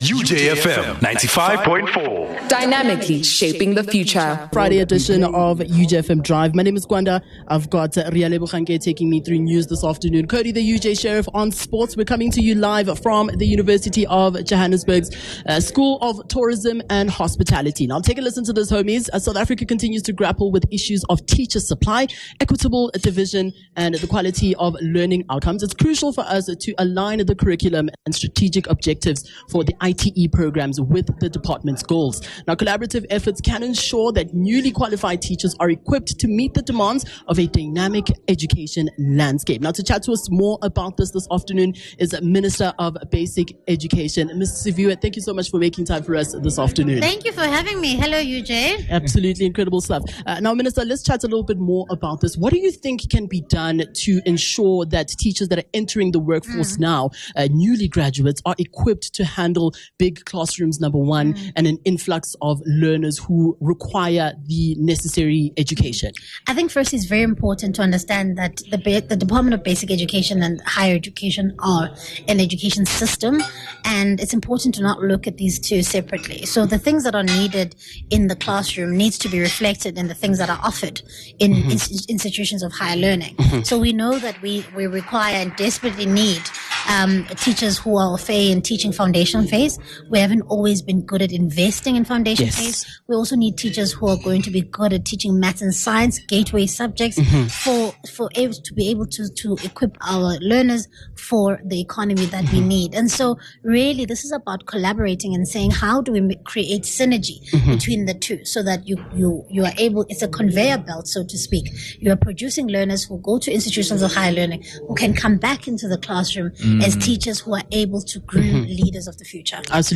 17 Jan UJ Hosting The Department of Basic Education - Interview with Minister of Education Siviwe Gwarube